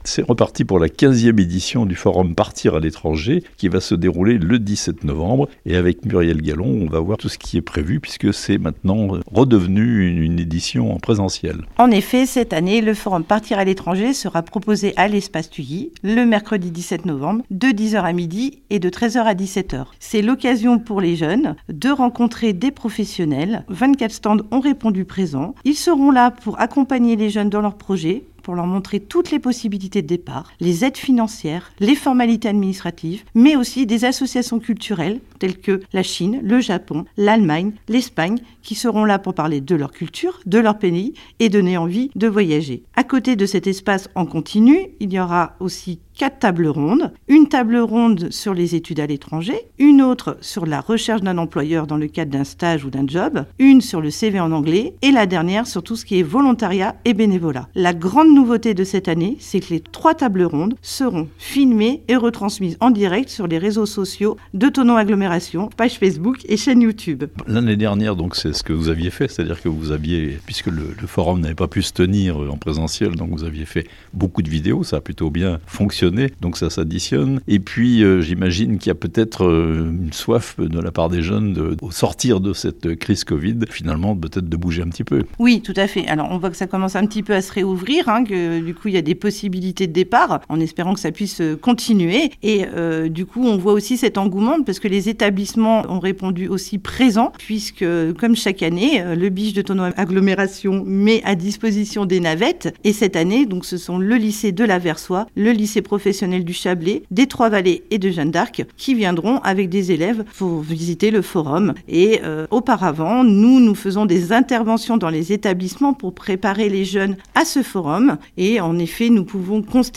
Le 15ème forum "Partir à l'Etranger" se prépare à Thonon (interview)